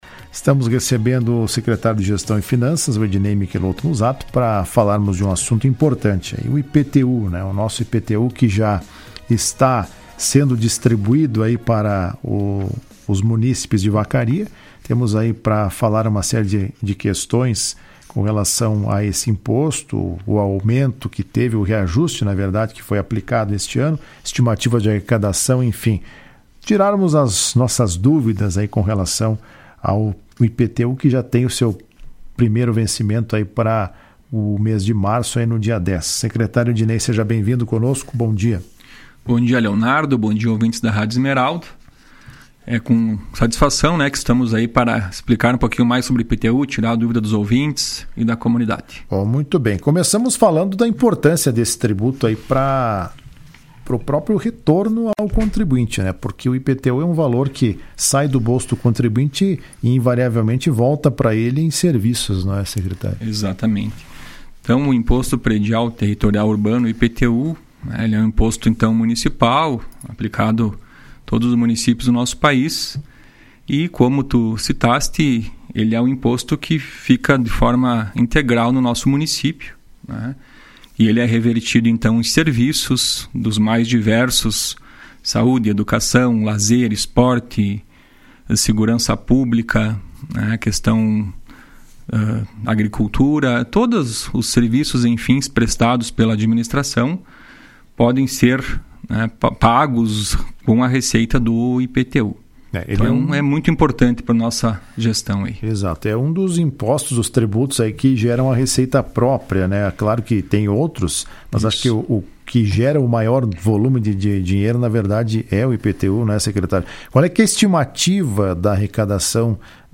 Em entrevista à Rádio Esmeralda nesta quinta-feira, o secretário de gestão e finanças do município, Edinei Michelotto Muzzato, disse que a estimativa de arrecadação dos tributos IPTU, ISSQN e alvarás de localização neste ano ficará em torno de R$ 12 milhões.